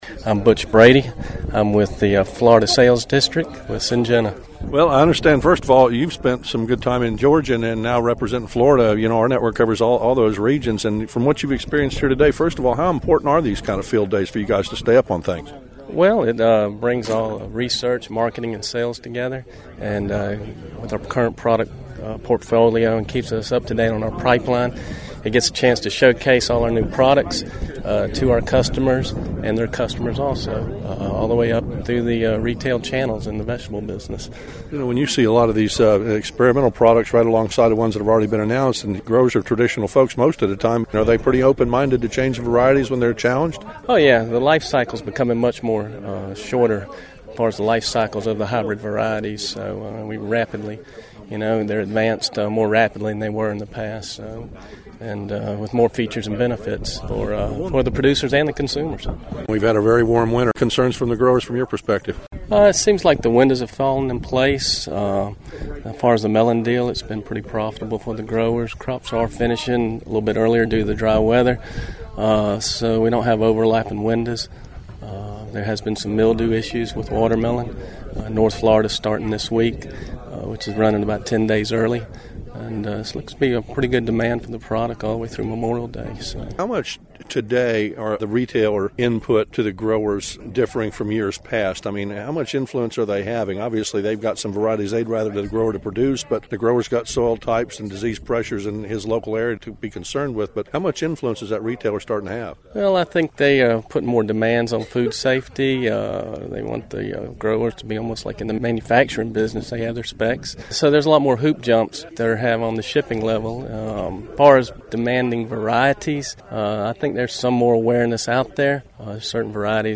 Southeastern growers, in this news post you can hear what’s being said in this crowd that will affect what’s grown this year on the farms in this deep southeast region.